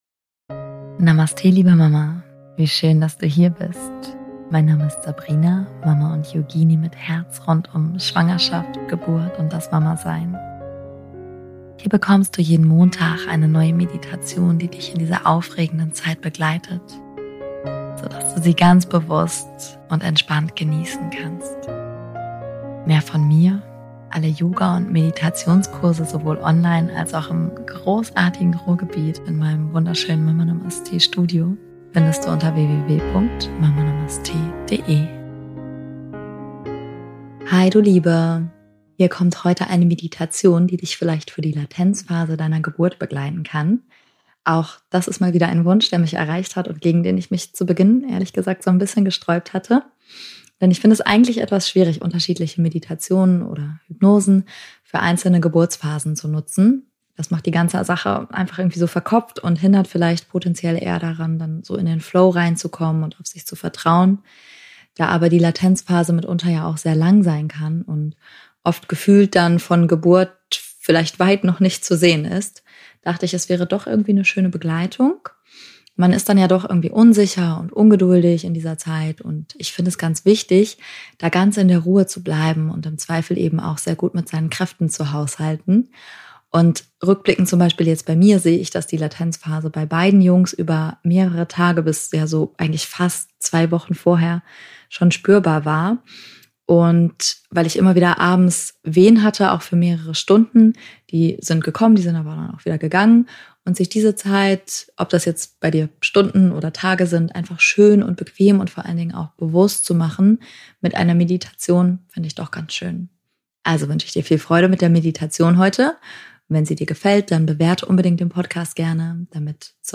In dieser Folge erwartet dich eine sanfte Meditation, die dich in der Latenzphase deiner Geburt begleiten kann – jener besonderen Zeit, in der sich dein Körper auf die aktive Geburt vorbereitet.